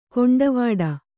♪ koṇḍavāḍa